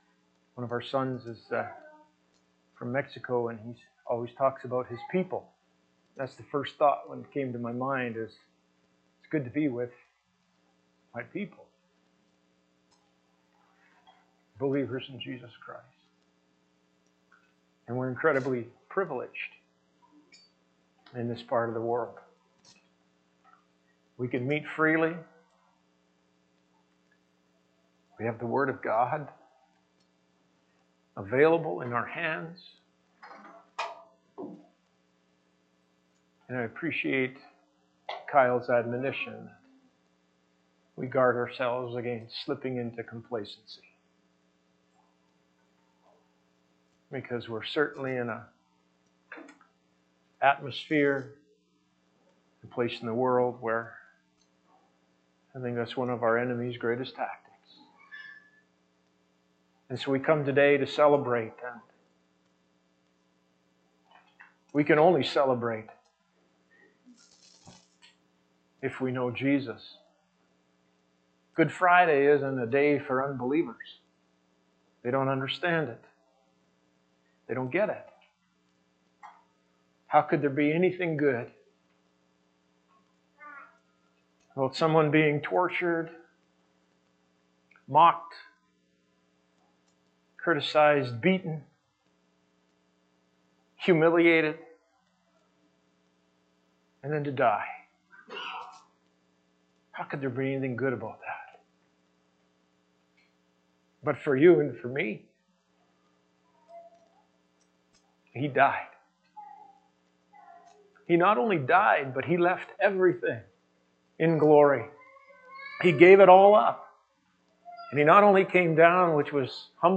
Good Friday Service